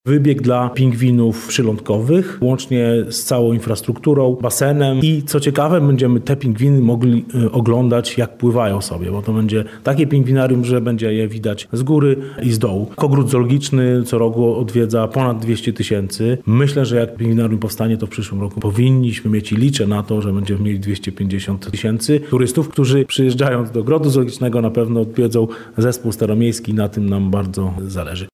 – Liczymy, że ta inwestycja przełoży się na jeszcze większy ruch turystyczny w naszym mieście – mówi prezydent Zamościa, Rafał Zwolak.